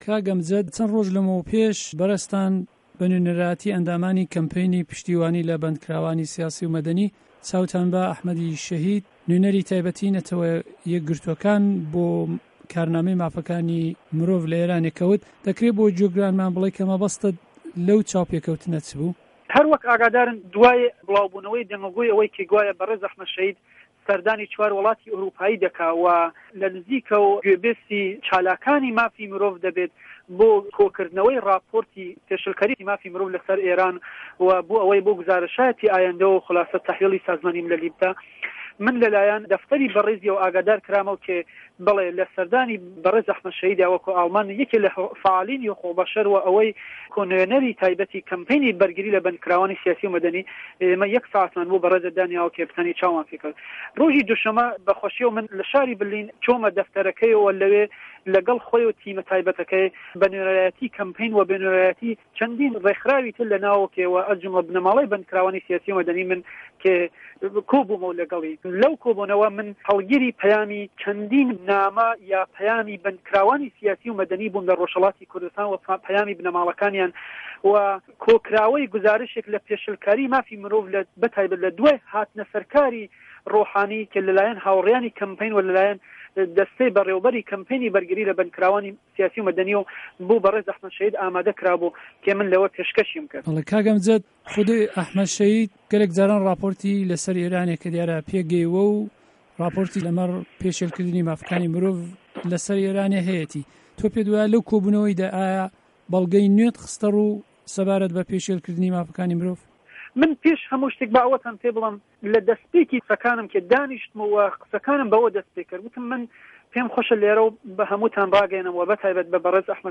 چاوپێکه‌وتن له‌ گه‌ڵ ئه‌حمه‌د شه‌هید سه‌باره‌ت به‌ پێ شێلکرانی مافه‌کانی مرۆڤ له‌ کوردستانی ئێران